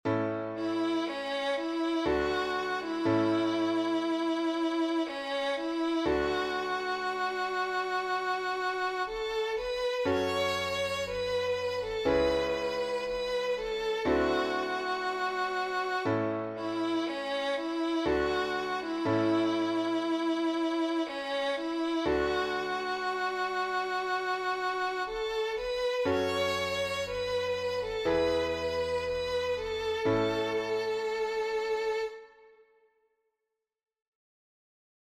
Hymn composed by